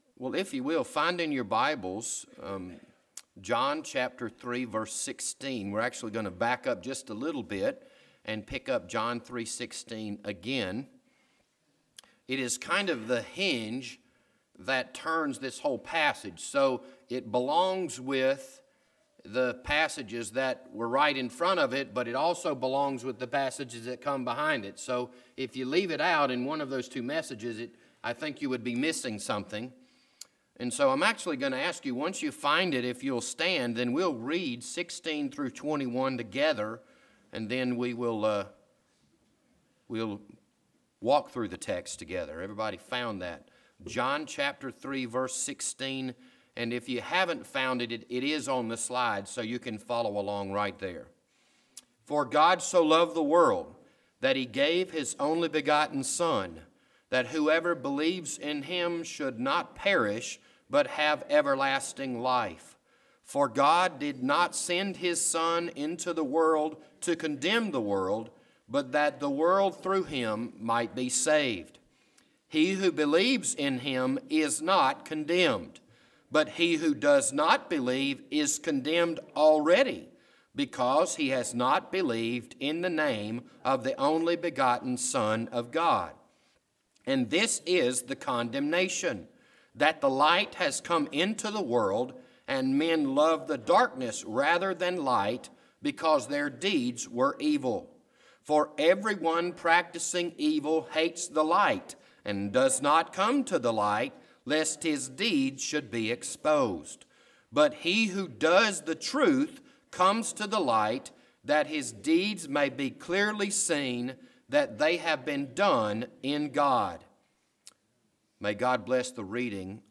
This Sunday morning sermon was recorded on March 24, 2019.